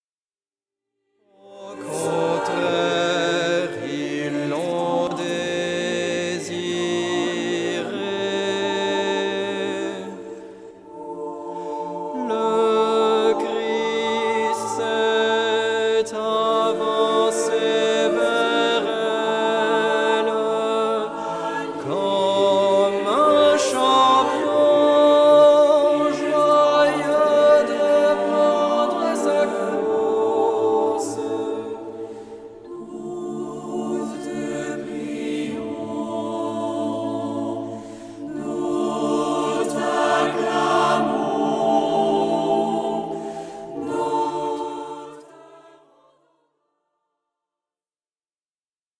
choeur